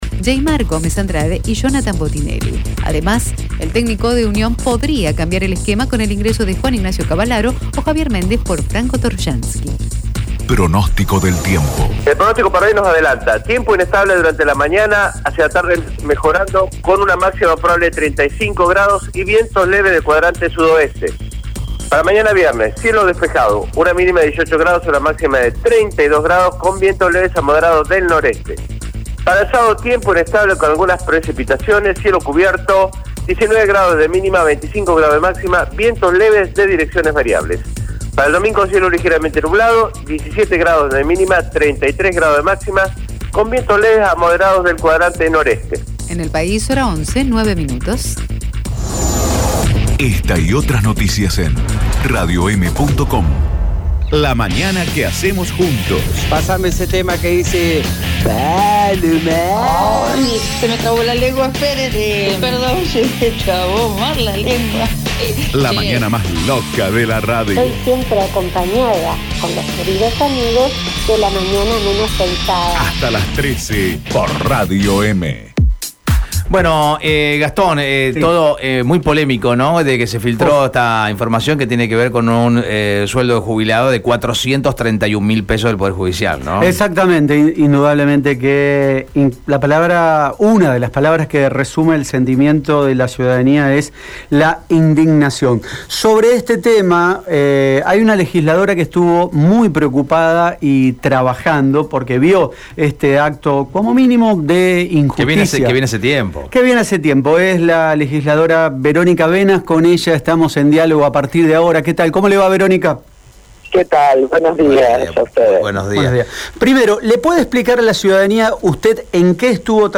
En dialogo con Radio EME, diputada provincial habló sobre la polémica desatada por los montos que perciben los jubilados del Poder Judicial.